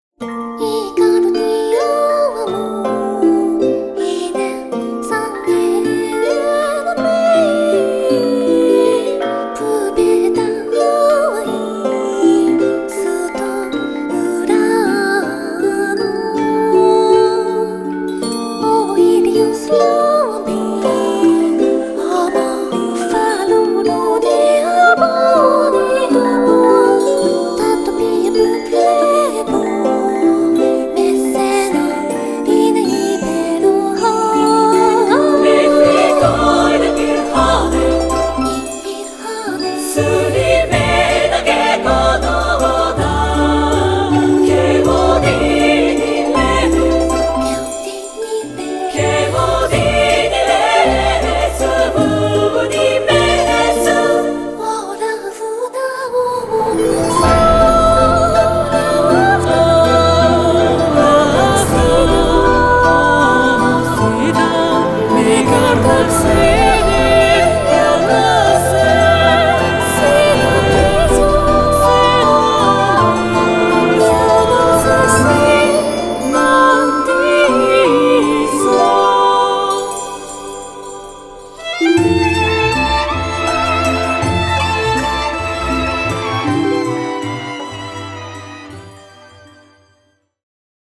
※再生中にノイズや歪みの様に聴こえる箇所がありますが、制作上の意図によるものです。ご了承下さい。